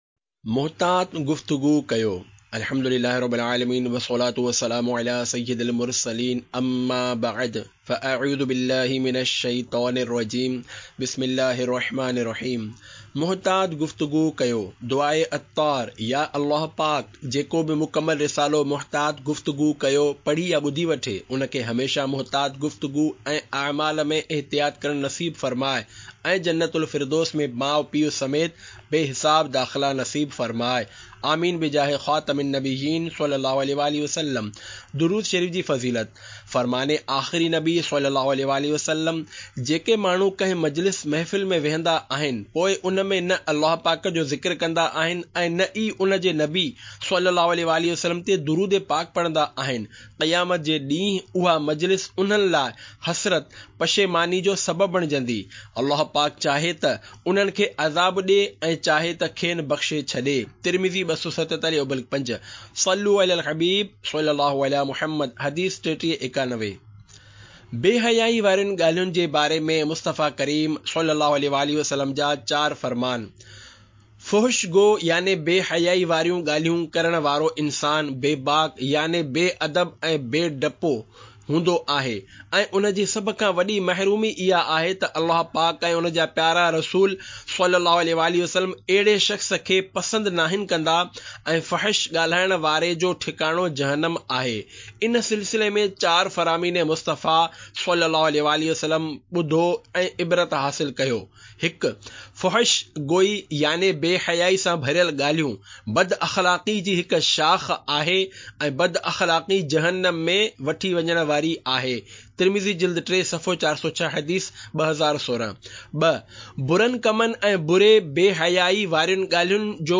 Audiobook - Muhtat Guftagu Kijiye (Sindhi)